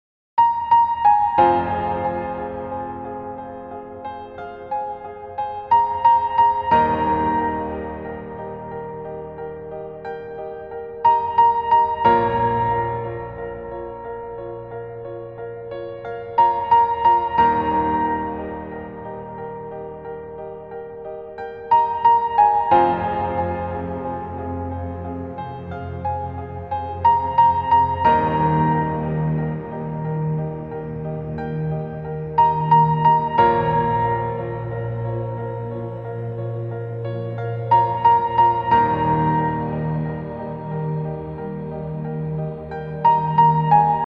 Rain
Rain falling on a cathedral
Rain_0.mp3